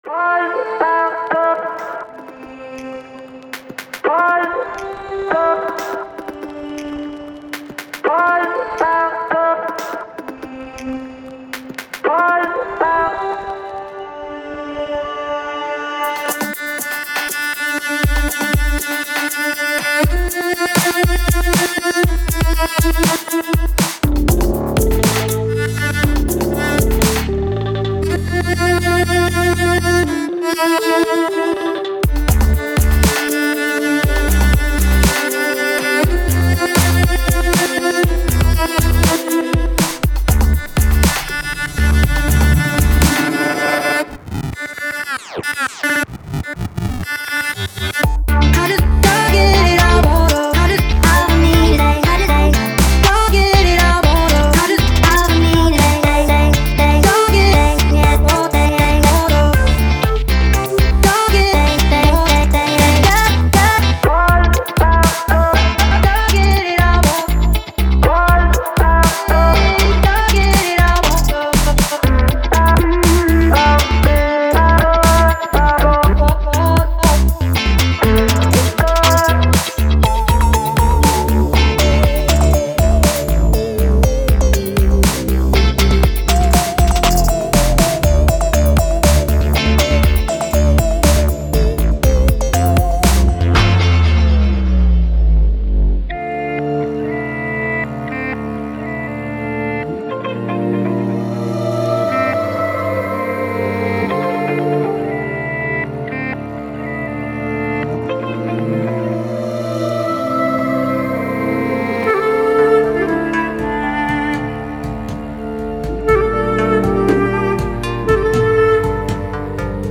Ghost-Talking (experimental
Мастеринг пока не делался, только разгон небольшой по громкости.